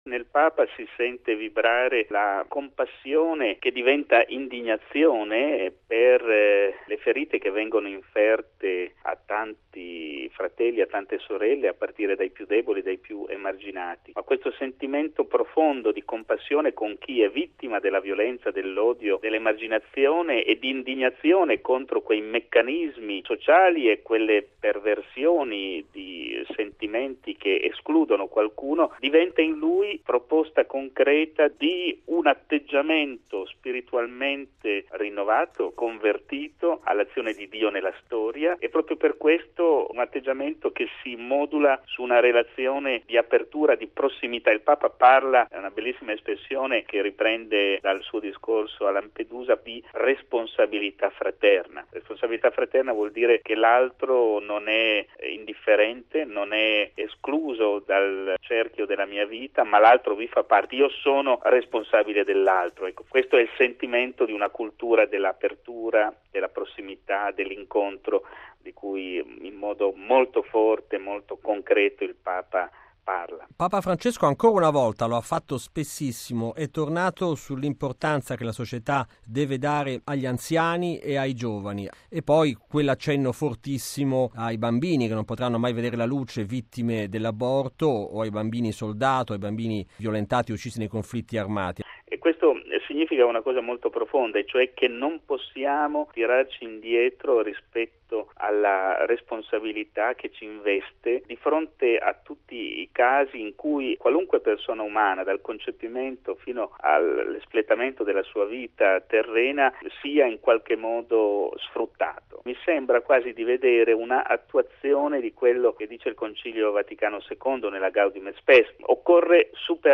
E' questo uno dei passaggi centrali del discorso che Papa Francesco ha rivolto ieri al Corpo diplomatico accreditato presso la Santa Sede. Per un commento, ascoltiamo